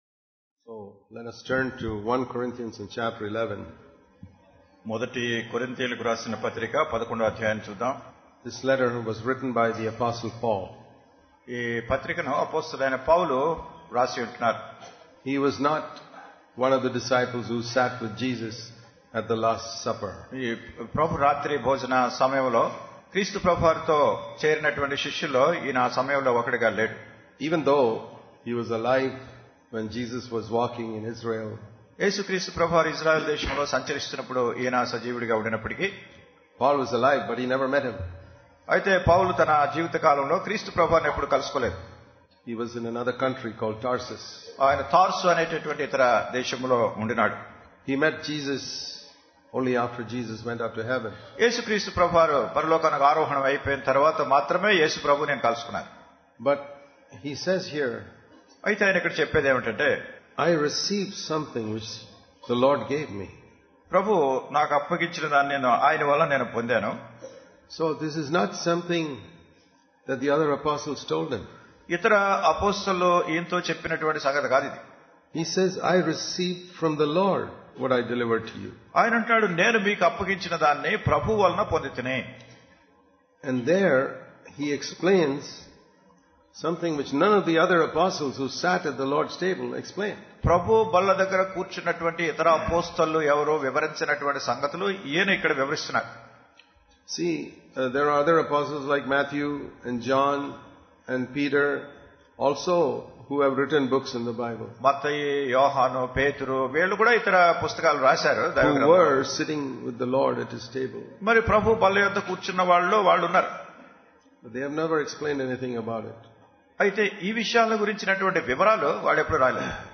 Kakinada Conference 2015 (with Telugu translation) Click here to View All Sermons